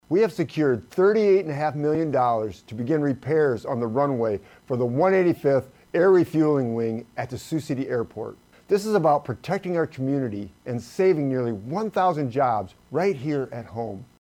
4TH DISTRICT CONGRESSMAN RANDY FEENSTRA SAYS IT’S A MAJOR WIN FOR SIOUX CITY AND NORTHWEST IOWA: